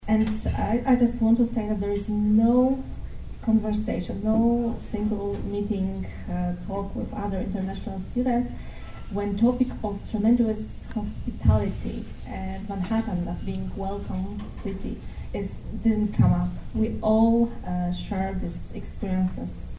The Manhattan Branch of the American Association of University Women held their annual celebration of International Women’s Day on Friday night.
The four guests spoke on their journeys to America and took questions from the audience.